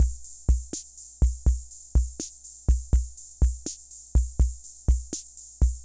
Loop2.wav